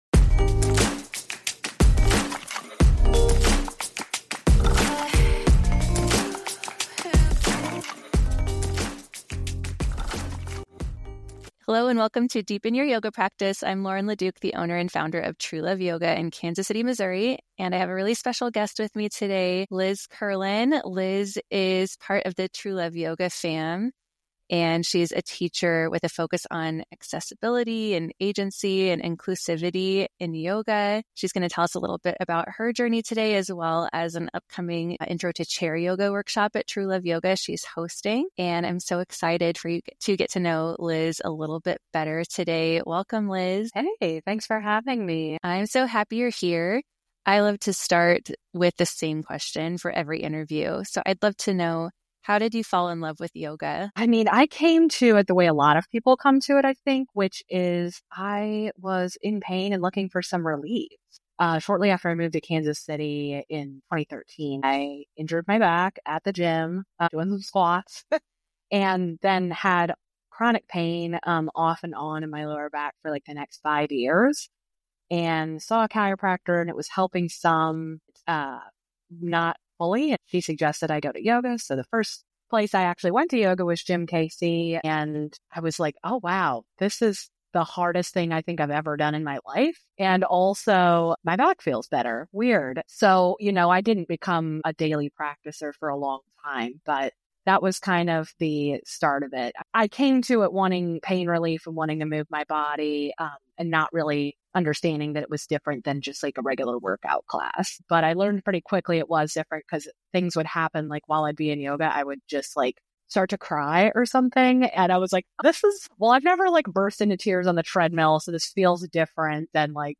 Whether you're a seasoned practitioner or just beginning your yoga journey, this podcast offers insightful conversations, expert interviews, and practical guidance to help you enrich and elevate your practice.